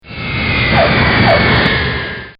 دانلود صدای سوت هواپیما 1 از ساعد نیوز با لینک مستقیم و کیفیت بالا
جلوه های صوتی